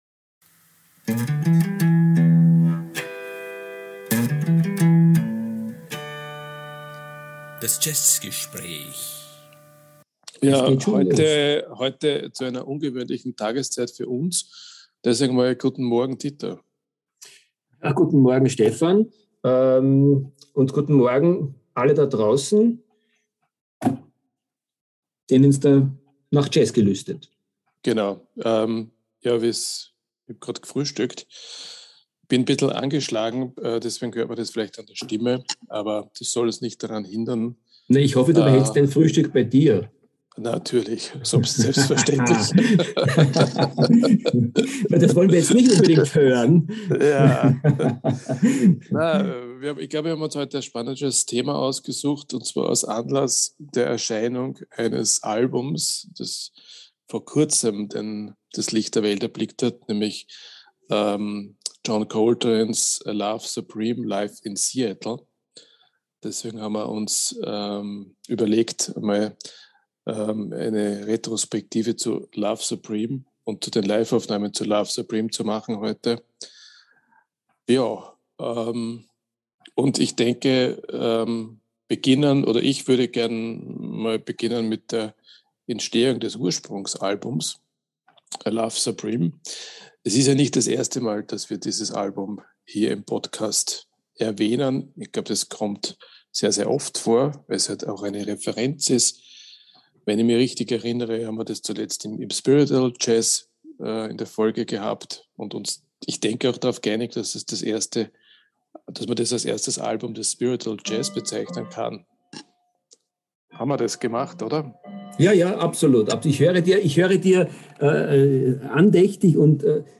Dieser Veröffentlichung widmen wir diese Folge unseres Jazzgesprächs. Darüber hinaus reden wir natürlich auch über die zweite bekannte Live-Aufnahme von Love Supreme, die im Sommer 1965 beim Jazzfestival Antibes entstand, sowie über die Aufnahme des Studioalbums im Dezember 1964.